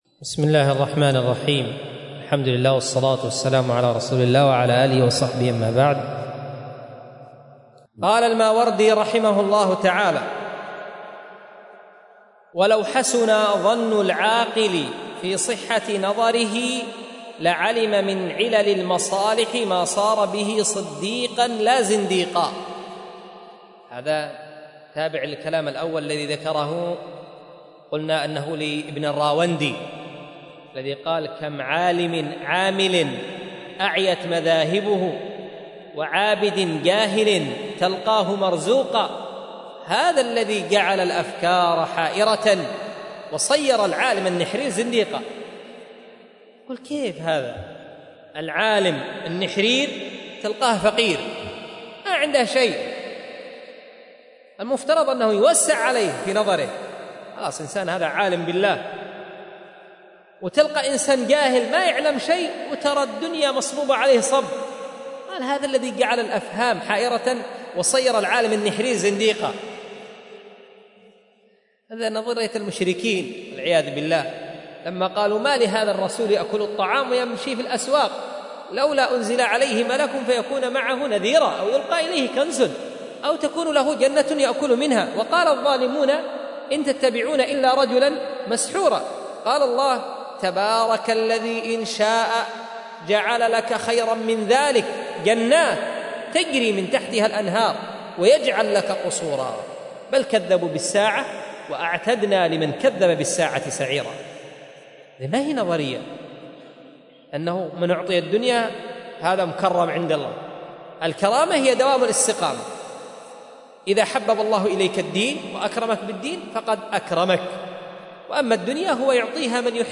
الدرس(  77 ) من تهذيب أدب الدنيا والدين.mp3